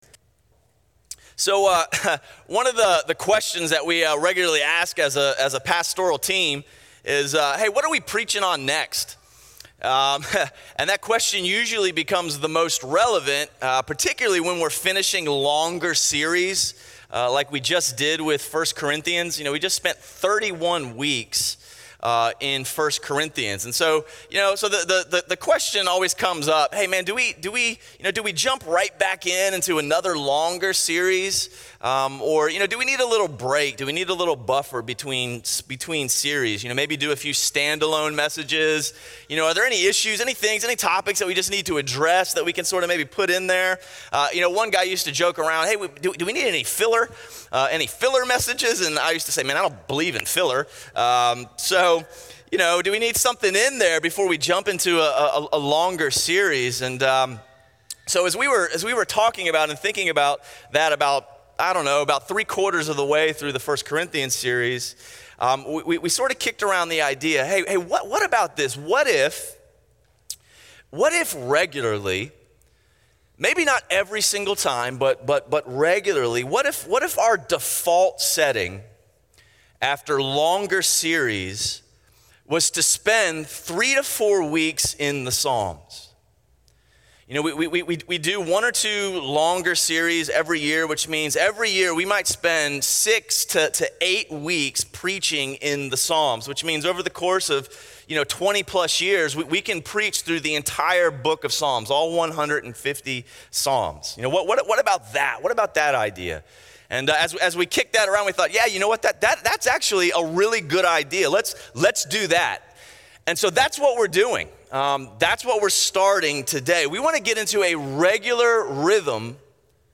A message from the series "Behold Him."